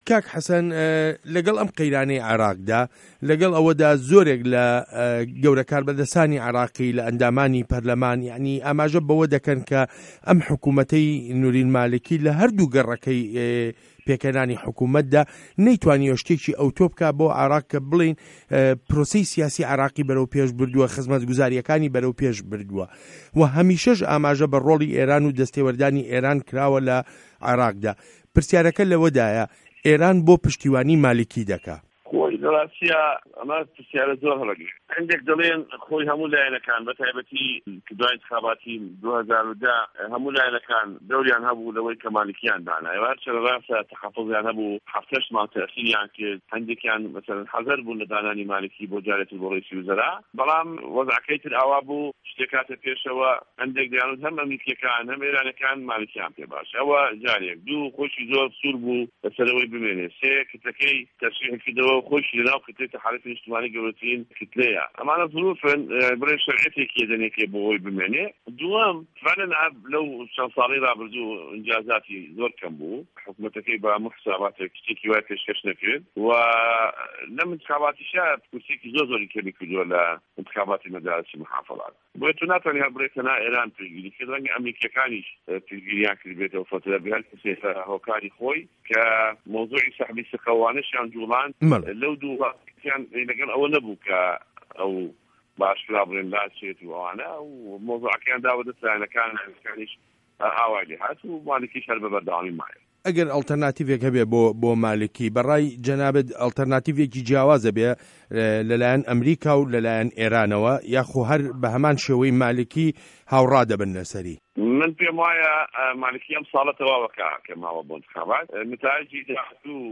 وتووێژ له‌گه‌ڵ حه‌سه‌ن جیهاد